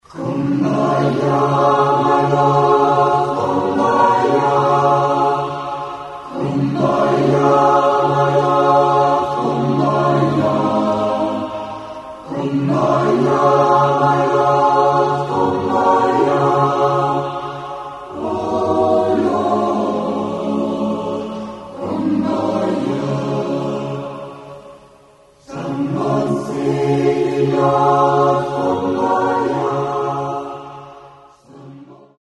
Demo-Aufnahmen - größtenteils Live-Mitschnitte.